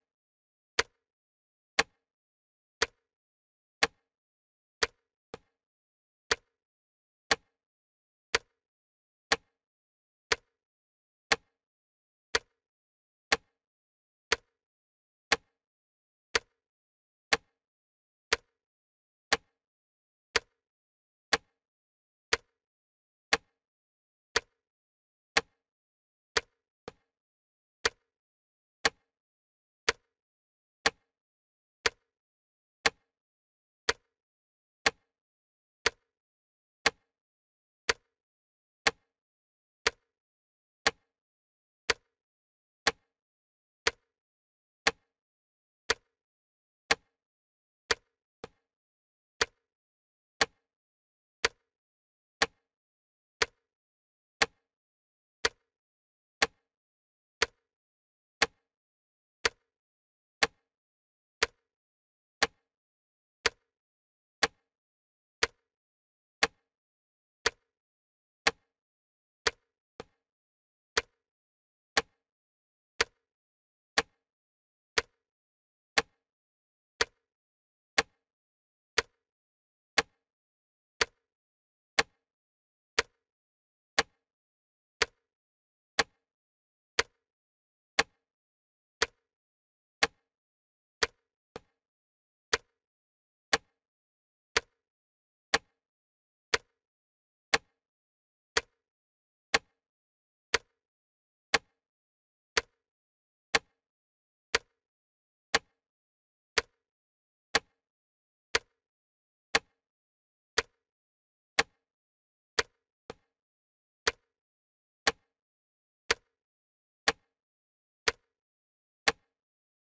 Эфир ведёт Антон Орехъ